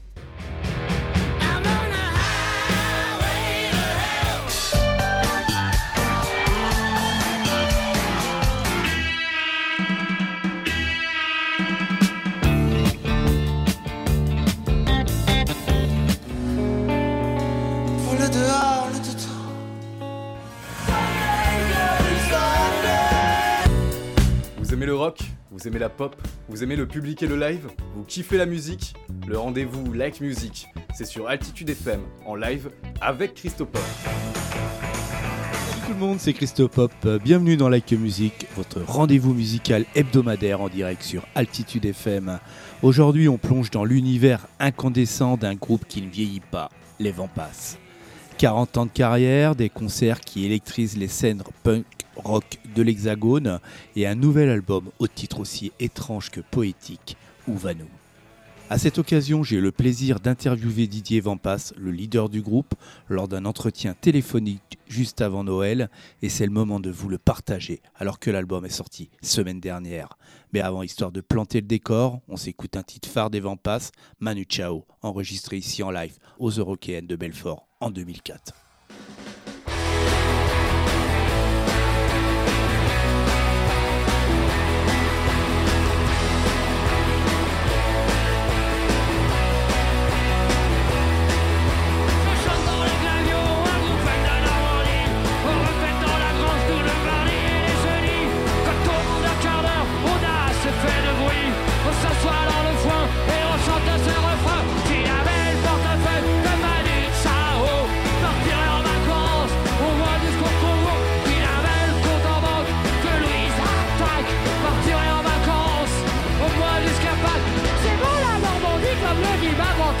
Like Music & Les Wampas @ Interview Didier Wampas
like-music-les-wampas-interview-didier-wampas.mp3